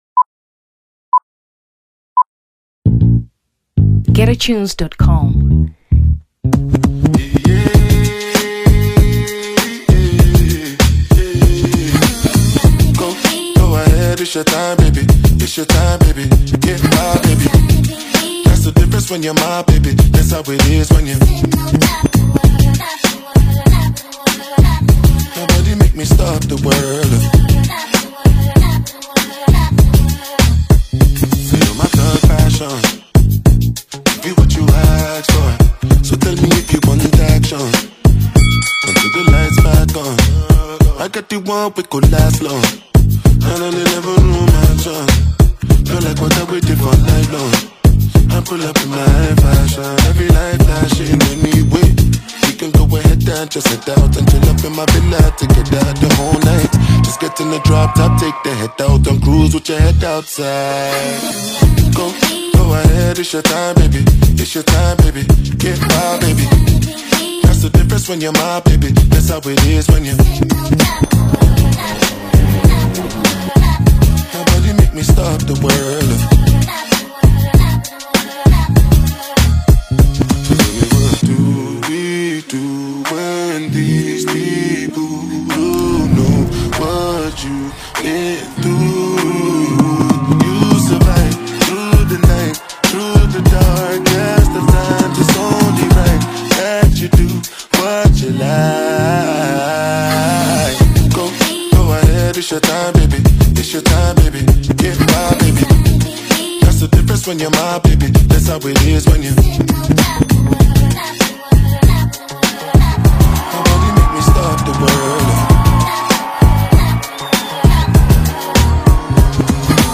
Afrobeats 2023 Nigeria